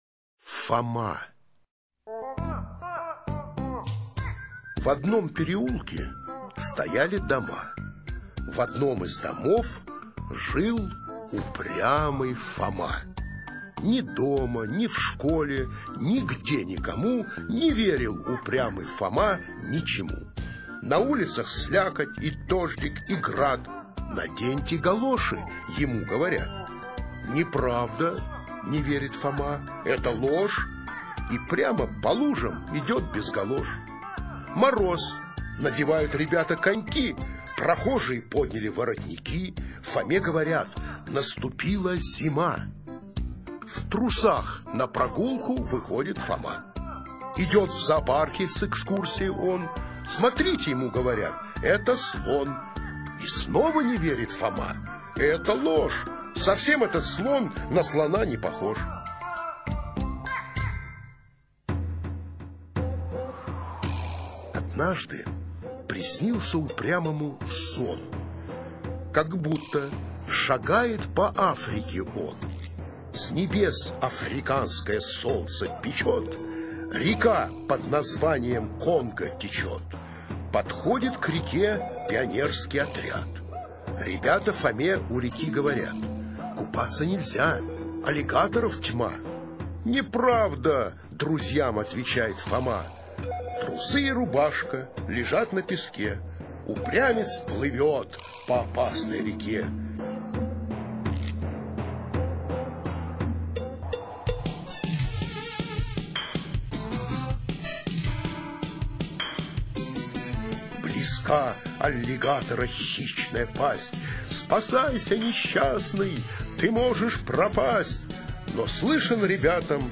5. «Читай ухом здесь – Фома (Сергей Михалков) читает Э. Виторган» /